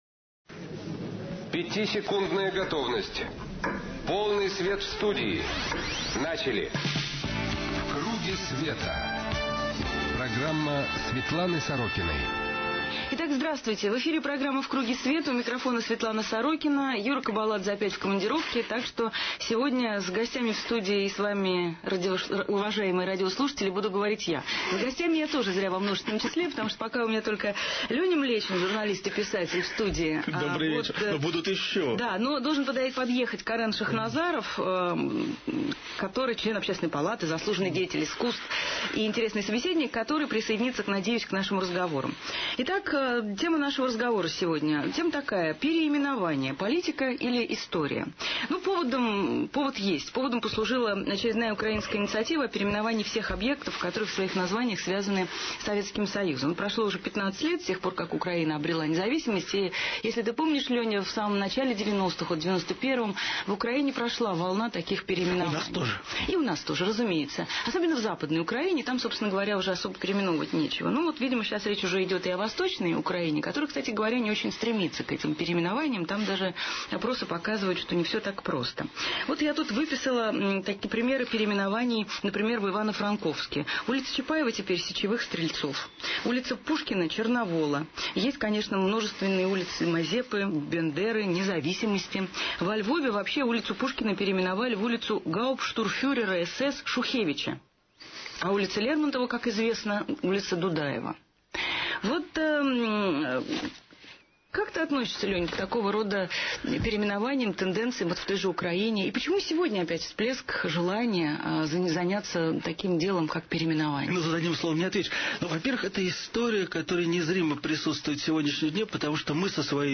Светлана Сорокина: передачи, интервью, публикации
Гости - Карен Шахназаров , Леонил Млечин . рубрикатор : Аудио: эфир – 51:28, 9 048 Кб Светлана: Здравствуйте!